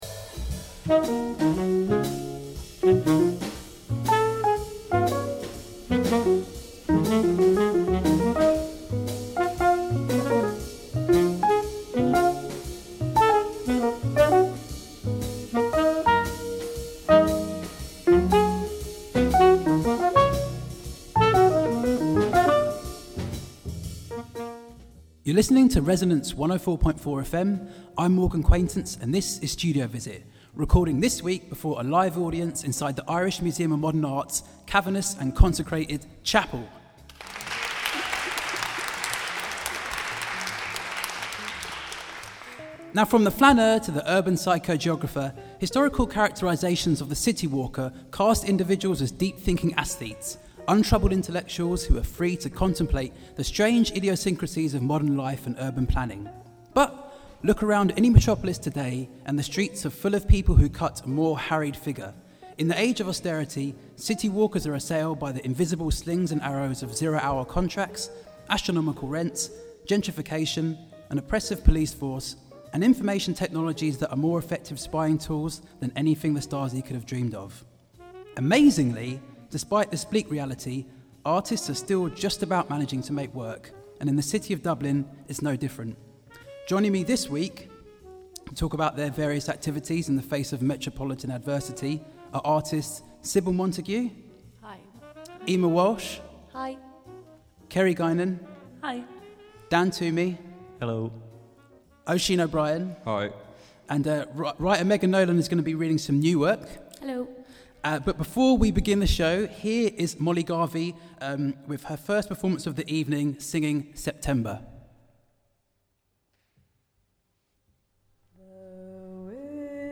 Recorded before a live audience at the Irish Museum of Modern Art